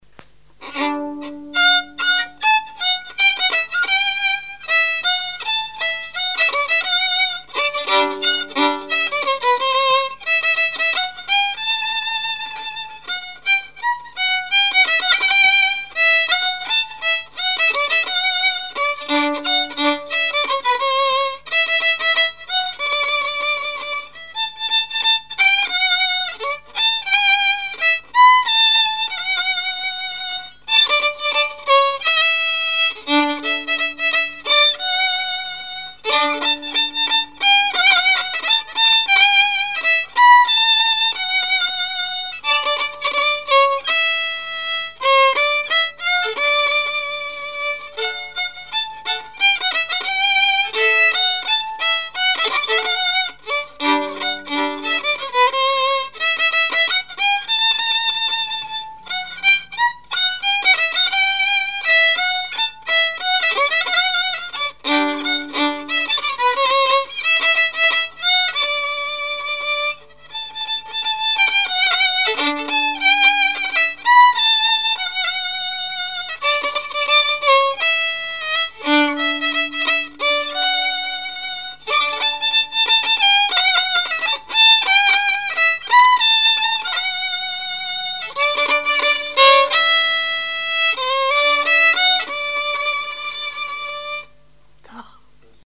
Répertoire musical au violon
Pièce musicale inédite